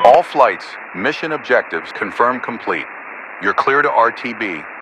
Radio-commandMissionComplete1.ogg